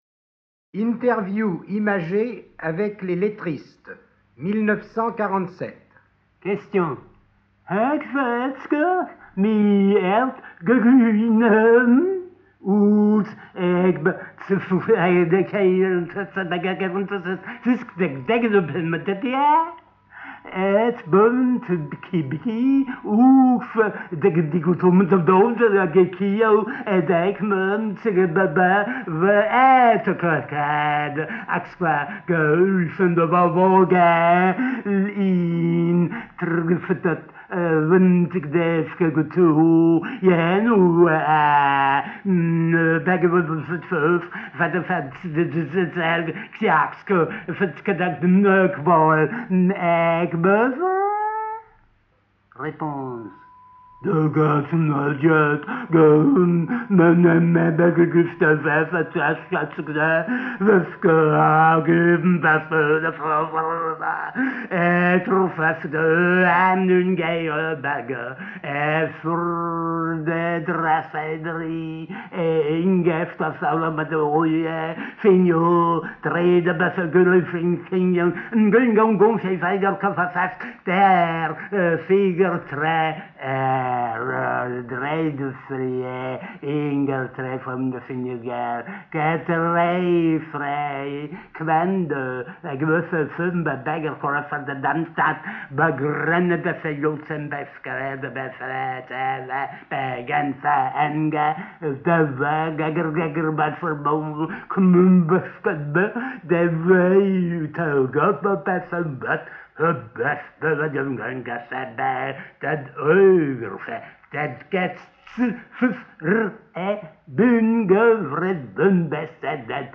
03 Interview avec les lettristes.mp3